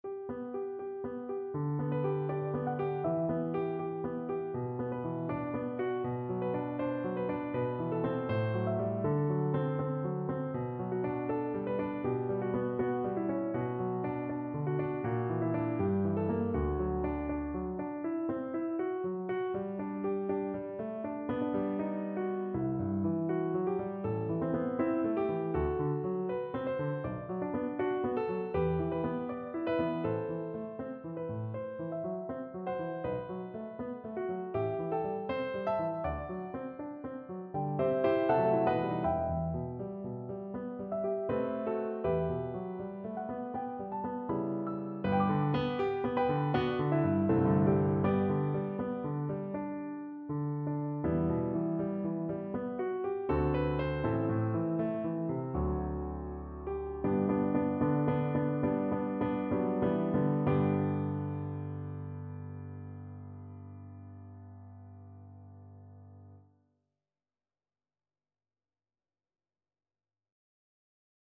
Piano version
No parts available for this pieces as it is for solo piano.
= 80 Moderato
4/4 (View more 4/4 Music)
Piano  (View more Advanced Piano Music)
Classical (View more Classical Piano Music)